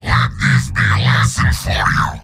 Giant Robot lines from MvM. This is an audio clip from the game Team Fortress 2 .